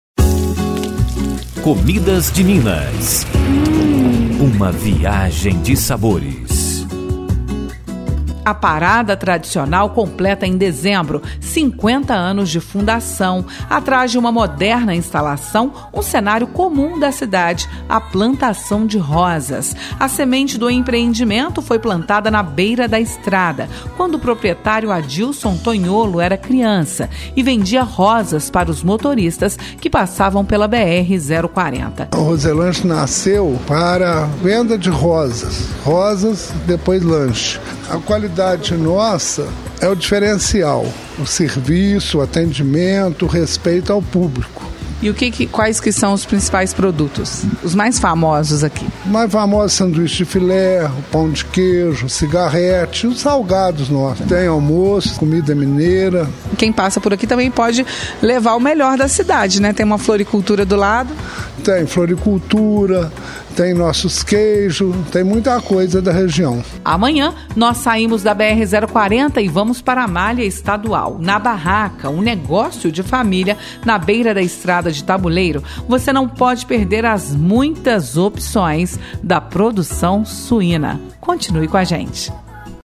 A Rádio Itatiaia produziu uma série de reportagens sobre quitutes e comidas típicas encontrados nas estradas.